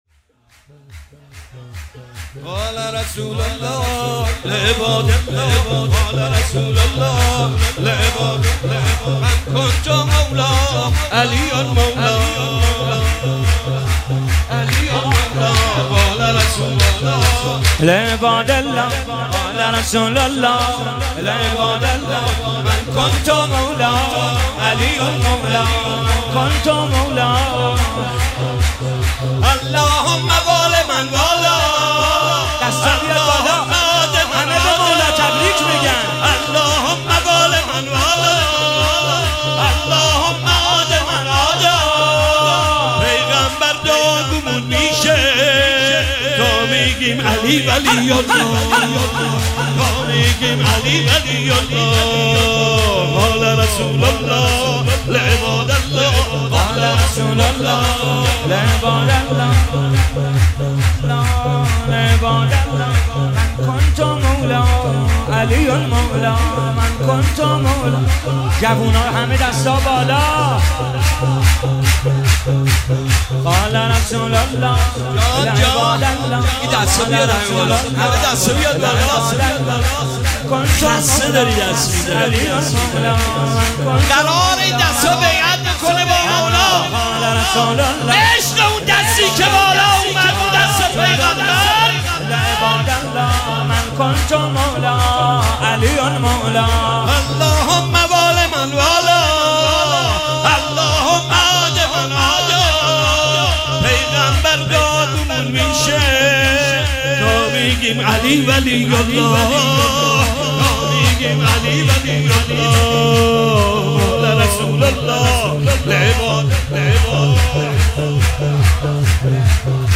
مداحی به سبک شور (جشن) اجرا شده است.